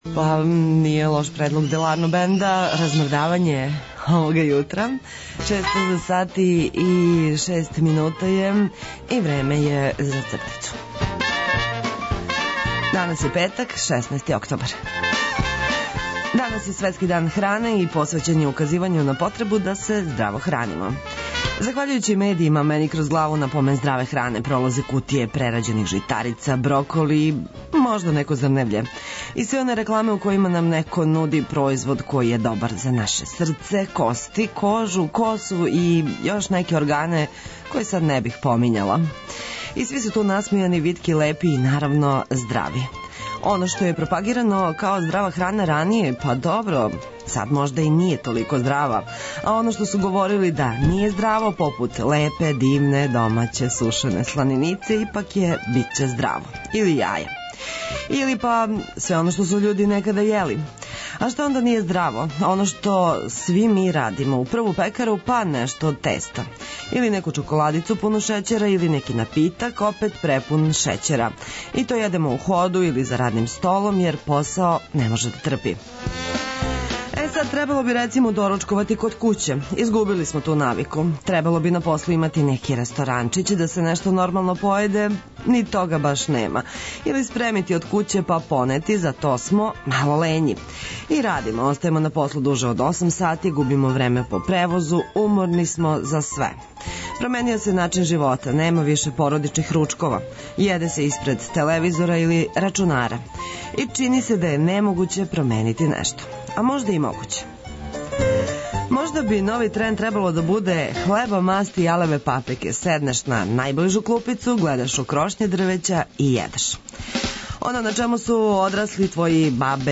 Овога јутра, на Светски дан хране, потрудићемо се да вам сервирамо један здрав доручак, у коме се налазе одлични музички ритмови, пакет занимљивости, зачињен са важним информацијама, вестима и графитима за добро јутро и Ваш осмех.